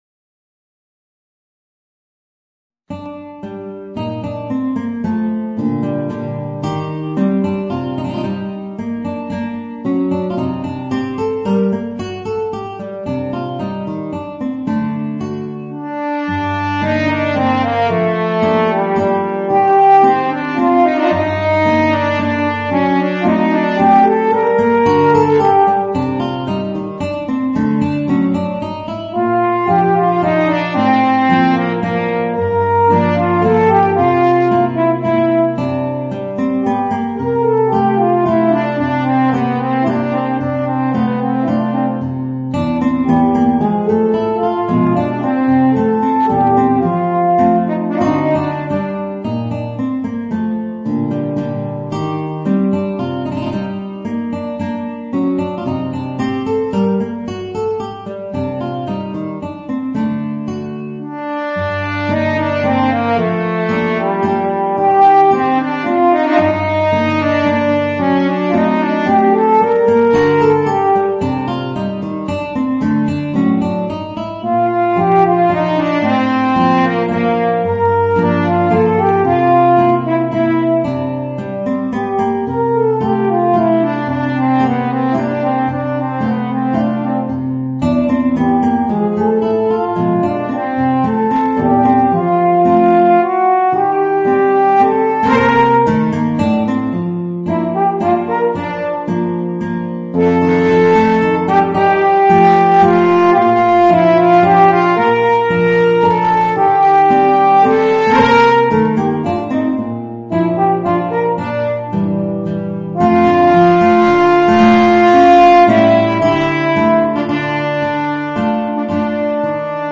Voicing: Horn and Guitar